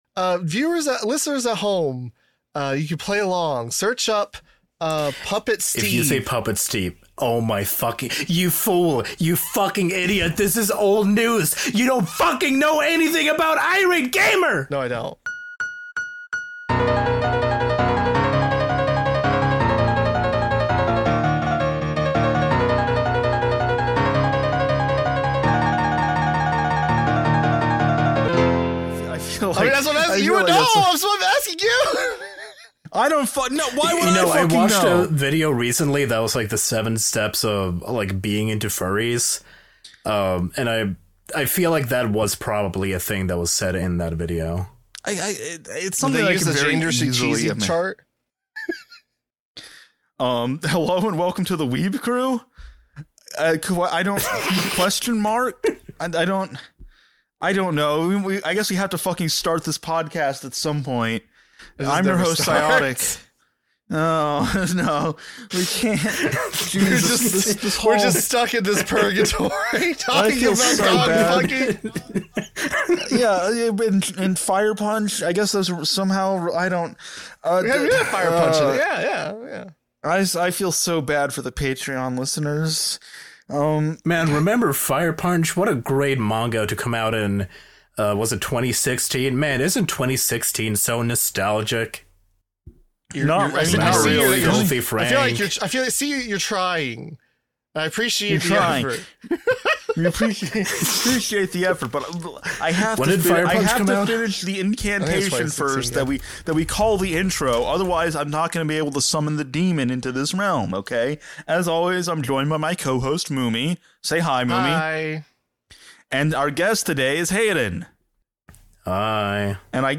1 The War Between the Land and the Sea Review 1:18:52 Play Pause 4h ago 1:18:52 Play Pause Play later Play later Lists Like Liked 1:18:52 Welcome THE TARDIS CREW: a Doctor Who podcast. Hosts